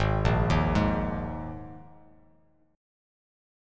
G#sus2 Chord